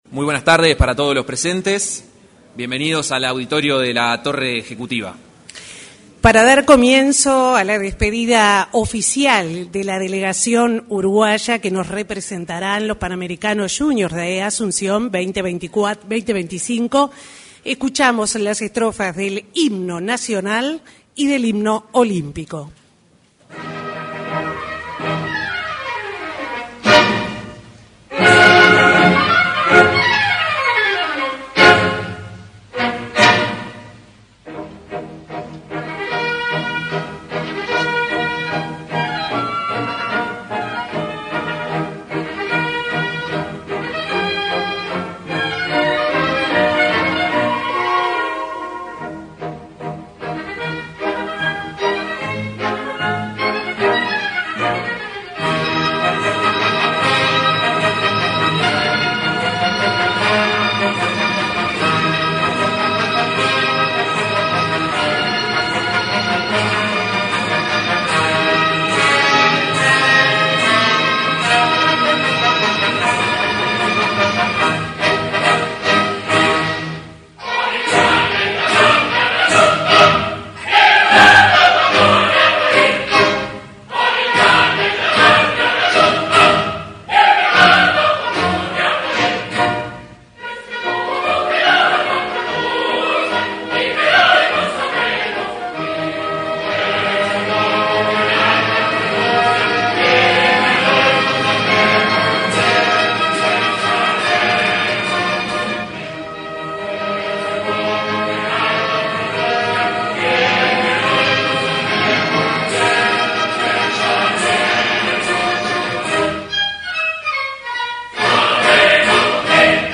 Palabras de autoridades en despedida de delegación para II Juegos Panamericanos Junior
Durante el acto de entrega del pabellón nacional a la delegación deportiva que representará a Uruguay en los II Juegos Panamericanos Junior Asunción